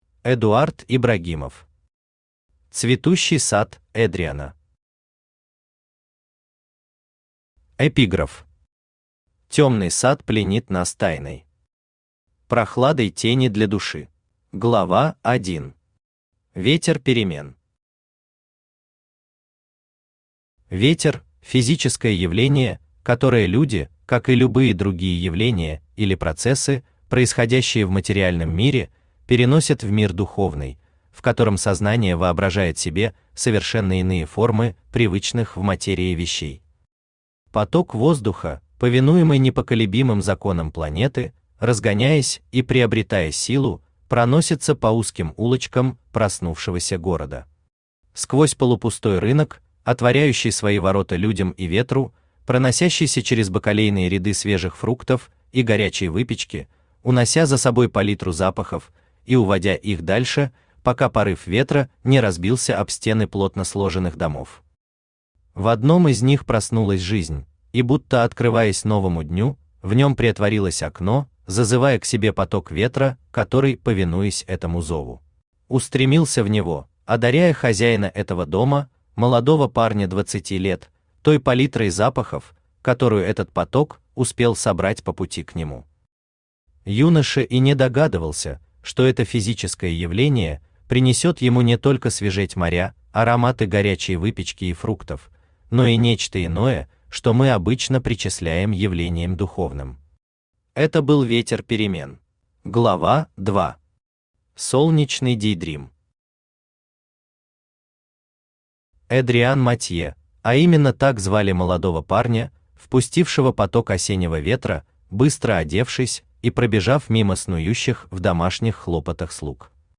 Аудиокнига Цветущий сад Эдриана | Библиотека аудиокниг
Aудиокнига Цветущий сад Эдриана Автор Эдуард Ибрагимов Читает аудиокнигу Авточтец ЛитРес.